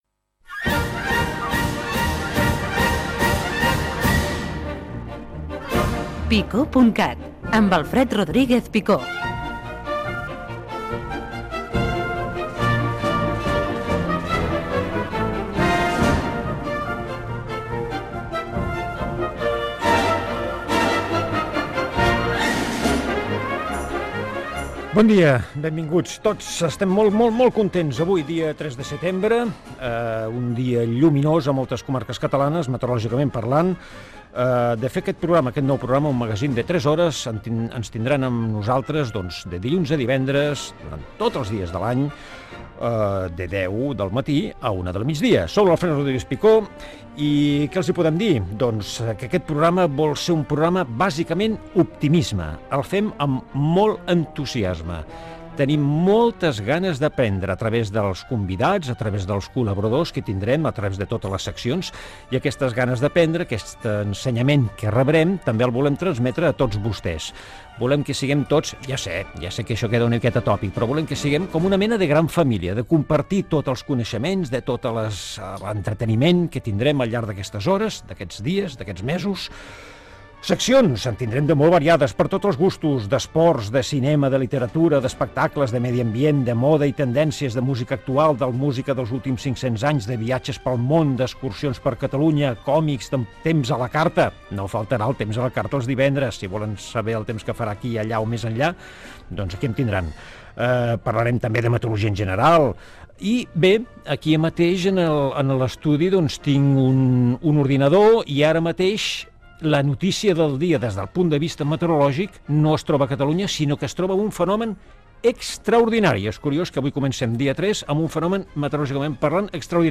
Careta i inici del primer programa. Presentació, objectiu, situació meteorològica, crèdits i fragment d'una entrevista al Conseller de Cultura, Ferran Mascarell.
Entreteniment